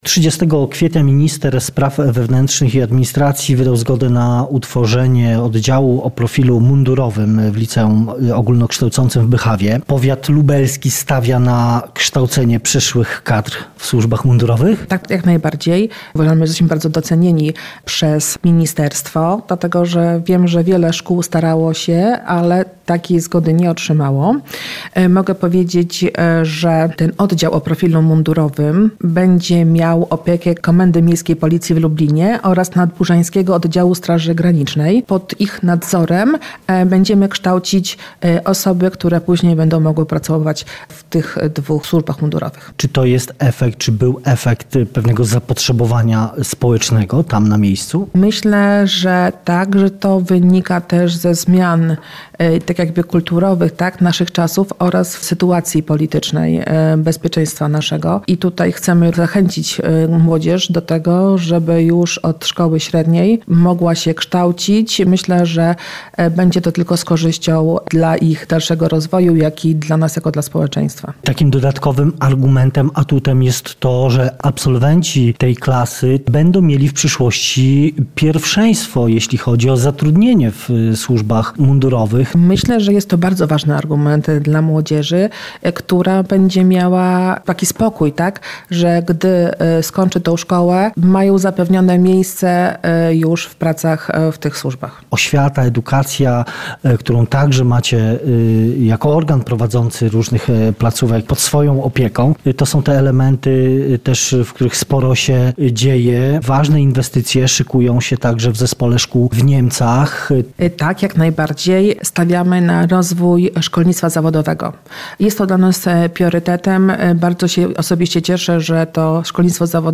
O inwestycjach i służbach mundurowych. Rozmowa z Sylwią Pisarek-Piotrowską, starostą lubelskim